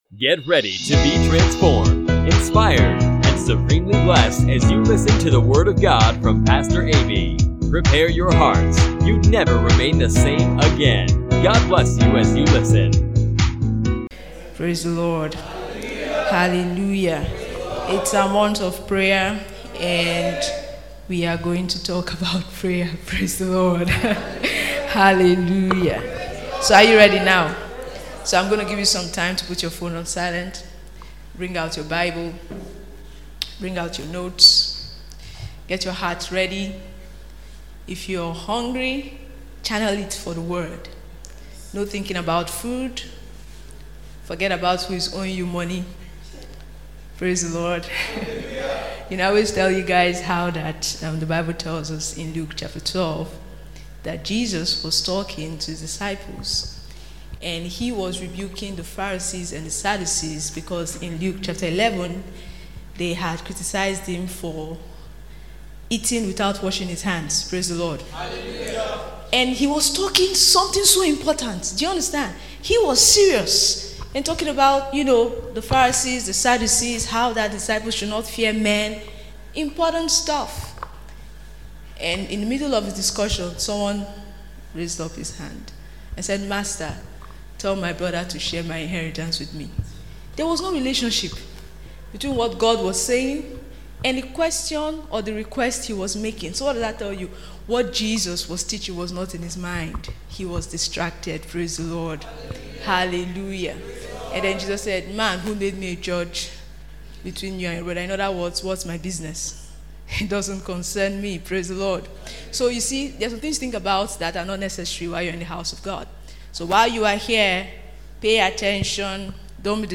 Jesus said, men ought- it is their responsibility- always to pray and not to faint; not to grow weary or give up simply because there seems to be a delay, he expected them to persevere in prayer until we see our answers. Find out more in this inspiring teaching by…